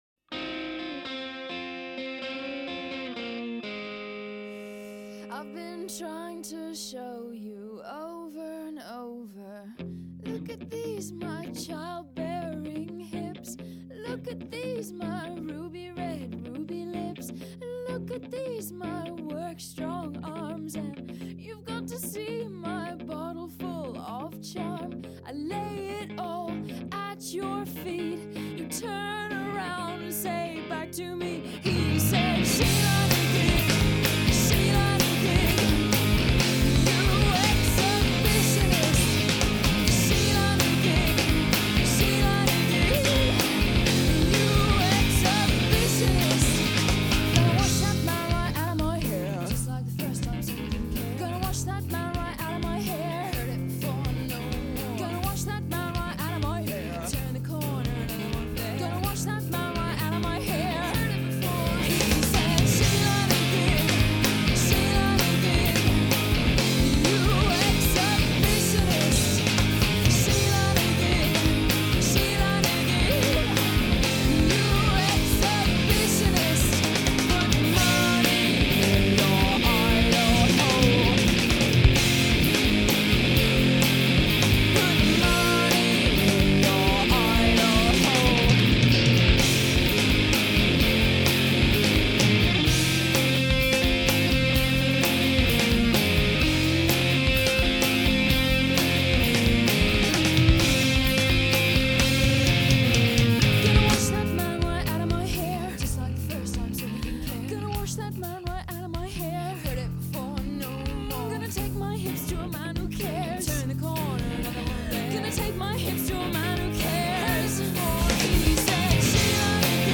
garagey, bluesy and occasionally down right dirty.